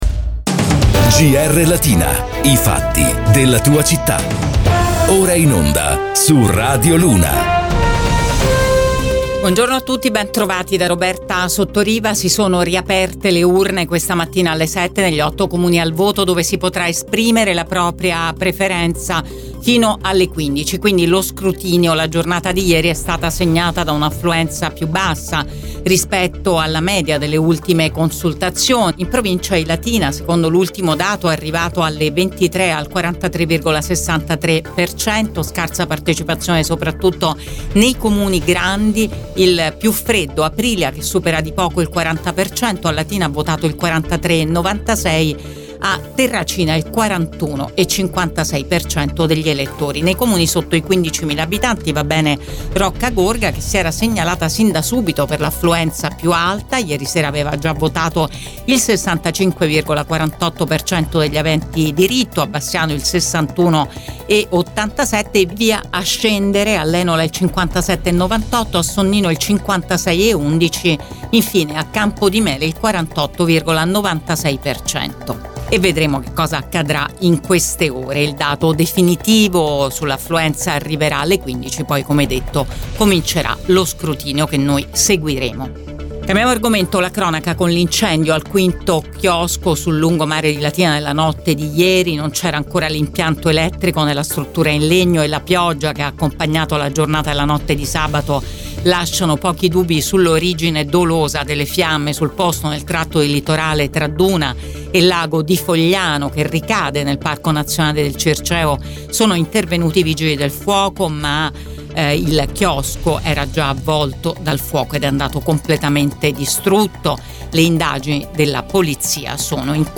LATINA – Qui puoi ascoltare il podcast di GR Latina in onda su Radio Immagine, Radio Latina e Radio Luna